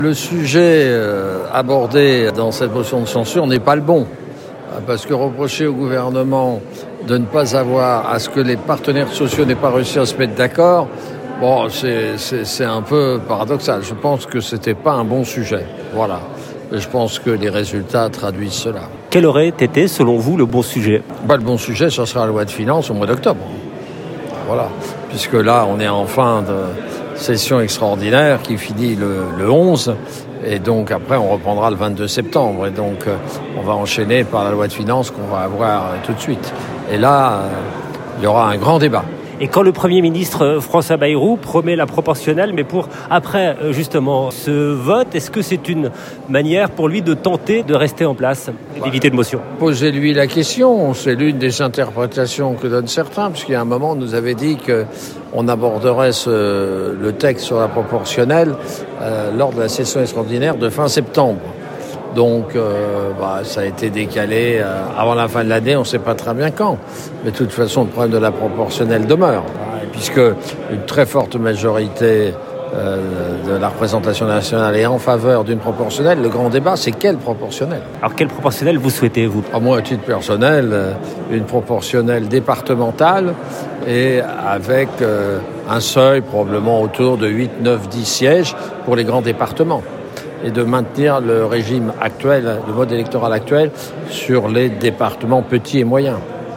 Déclaration du député centriste, rapporteur du budget à l'Assemblée nationale Charles de Courson